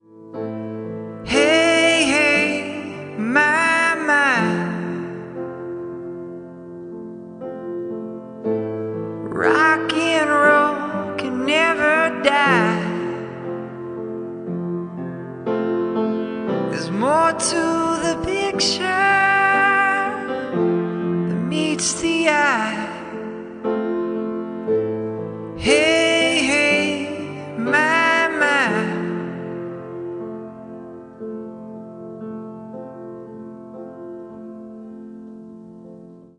• Качество: 192, Stereo
красивые
спокойные